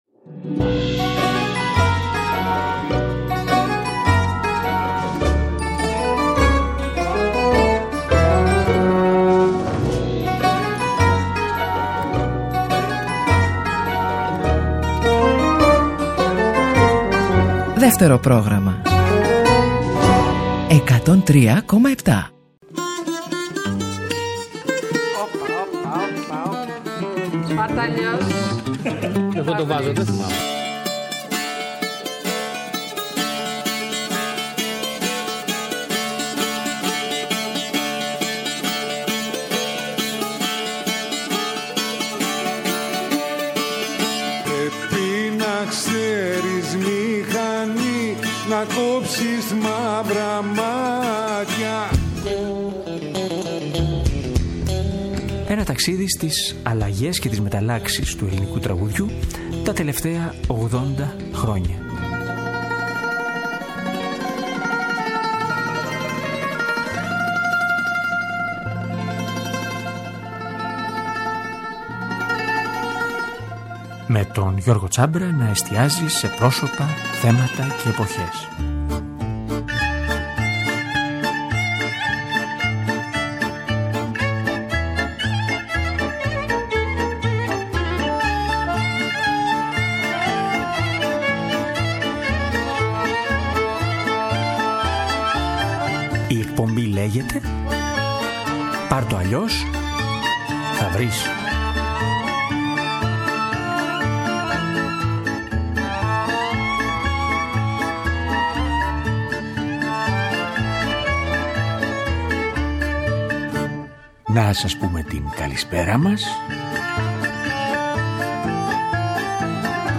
Τα τραγουδούν ο Γιώργος Νταλάρας και ο Γιάννης Καλατζής.